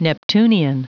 Prononciation du mot neptunian en anglais (fichier audio)